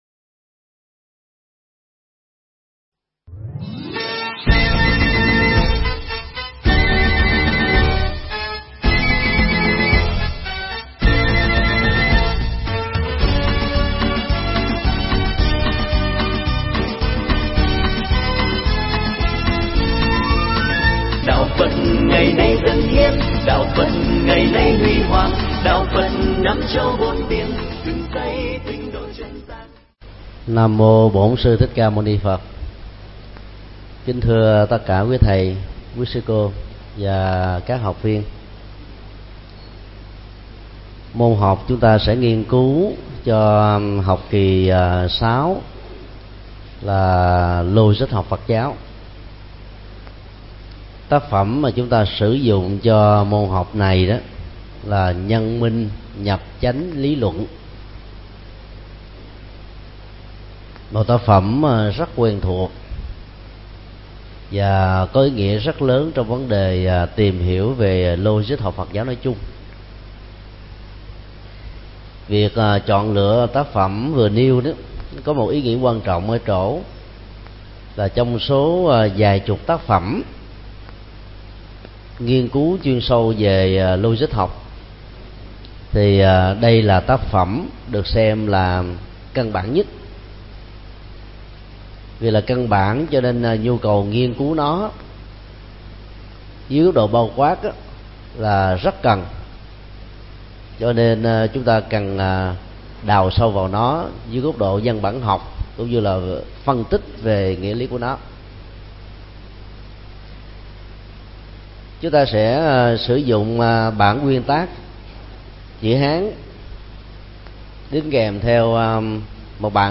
Mp3 Pháp Thoại Mục Đích Của Nhân Minh
Giảng tại Học viện Phật giáo Việt Nam tại TP. HCM